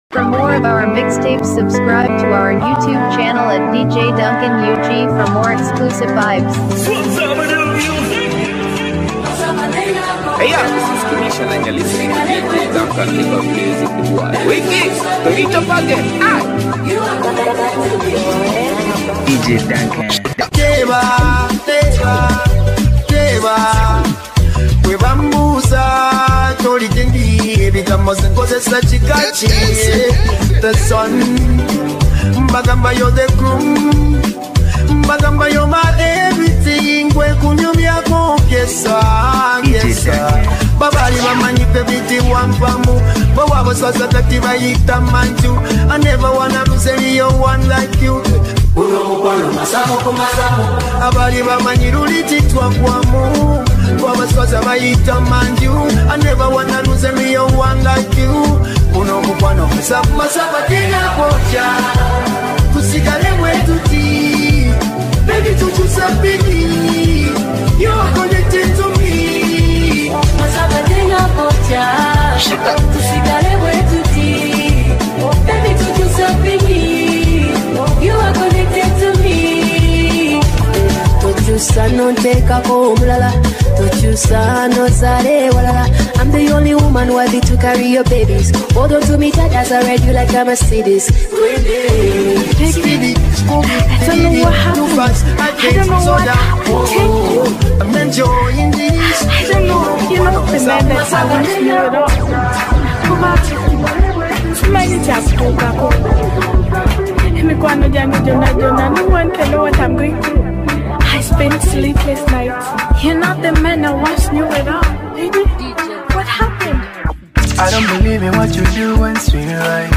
Download free Nonstop Ugandan Music